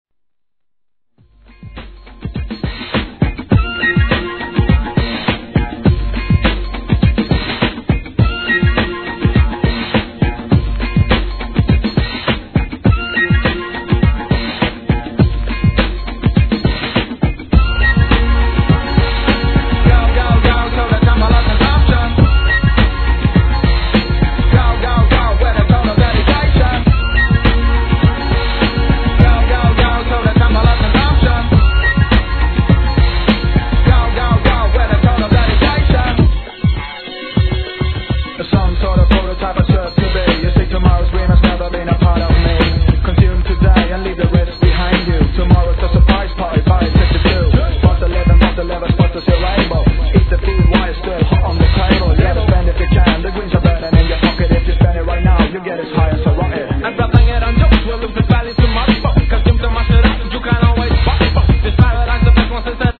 C / 盤面キズ多いです
HIP HOP/R&B
(102 BPM)